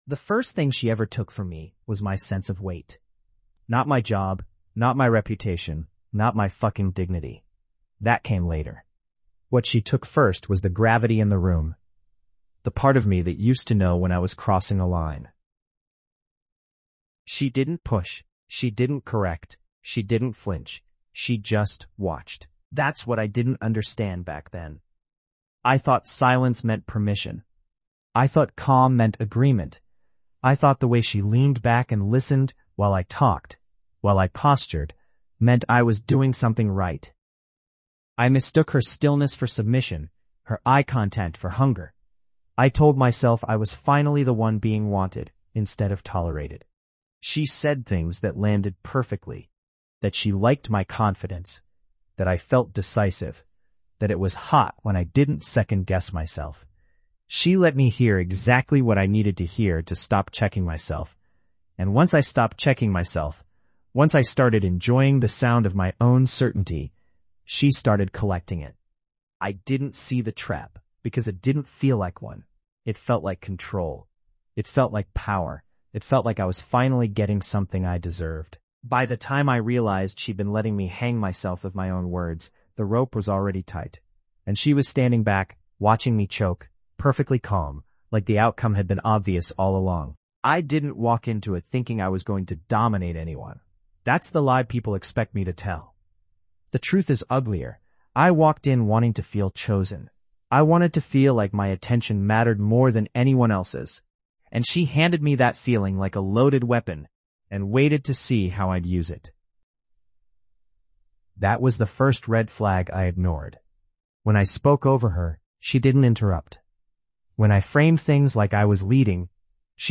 In this episode, a man recounts how a woman carefully fed his ego—praising his decisiveness, encouraging his dominance, letting him believe he was leading the dynamic—while quietly documenting every line he crossed.